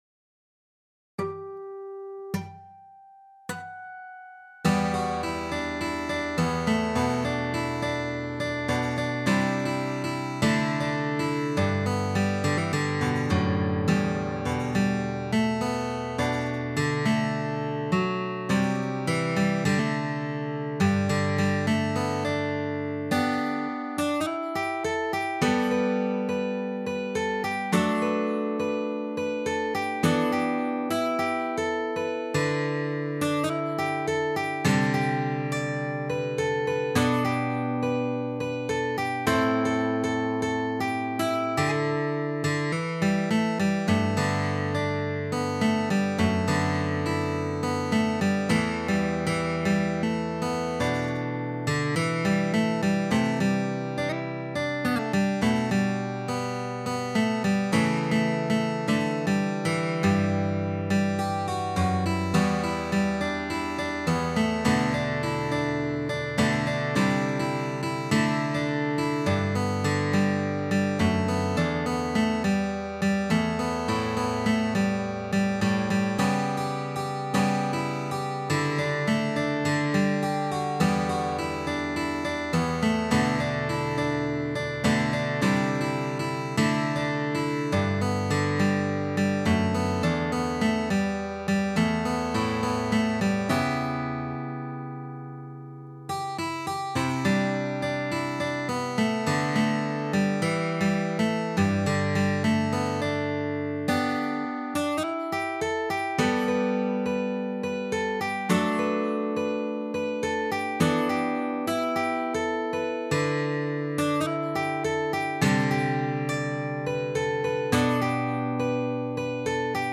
指弹谱
原曲是F调，改编采用了G调指法，降低了演奏难度。